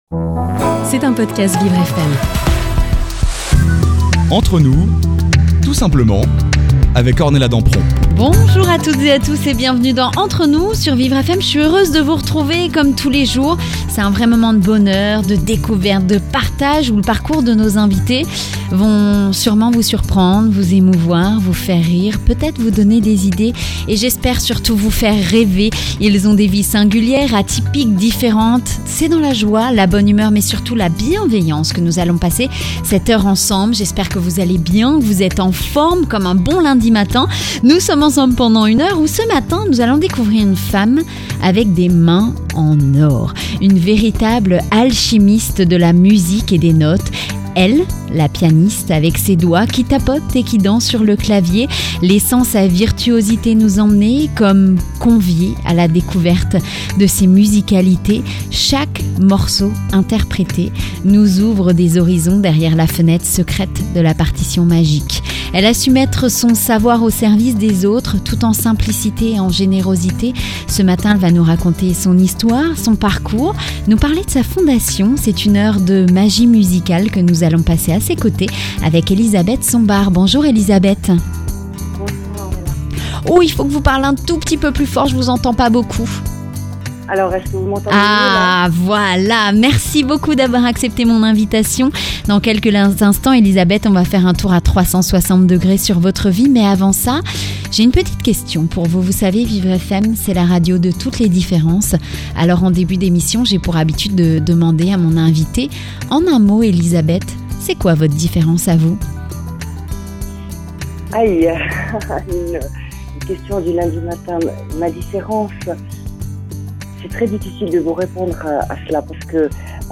Interviews audio • Suisse